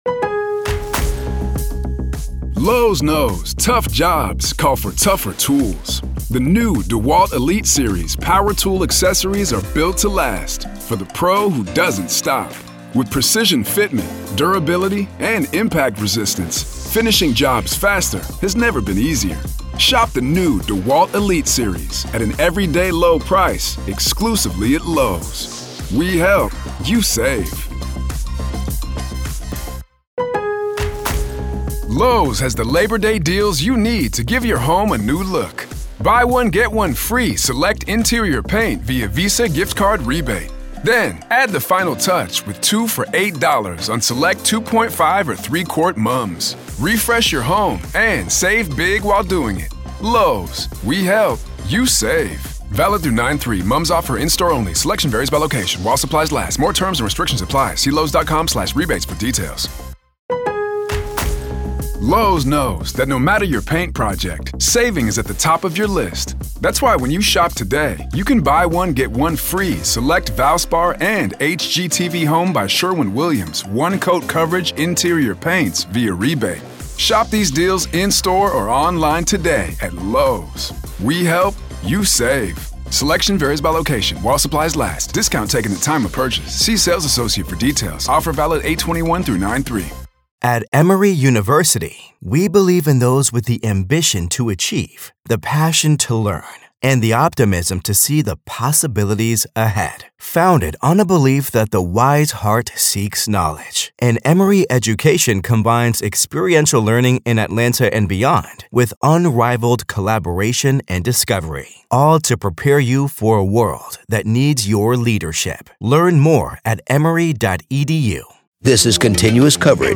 Join us this weekend on our riveting podcast as we journey through the most captivating interviews and enthralling audio snippets that delve into the enigmatic case against Lori Vallow Daybell.